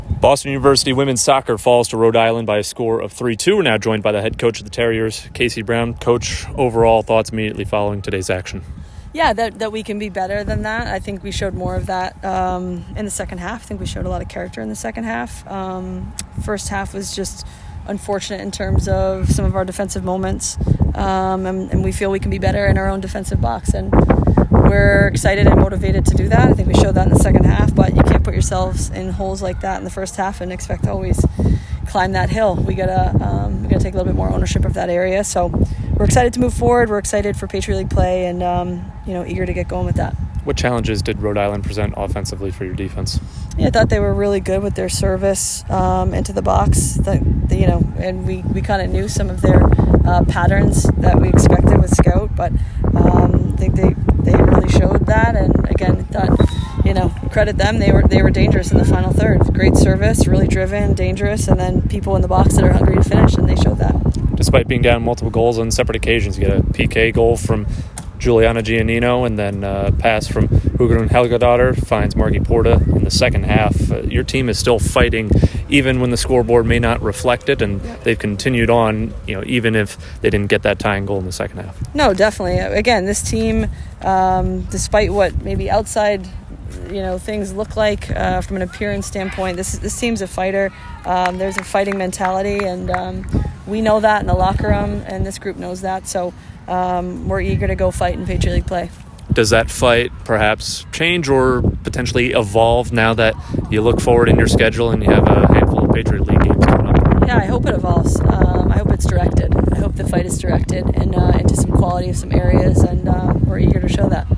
Women's Soccer / Rhode Island Postgame Interview